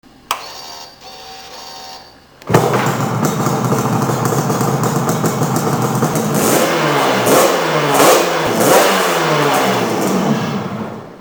Download: Hear it start and rev (mp3) Specifications Brochure Red Brochure Yellow
yamaha rz350 starts & revs.mp3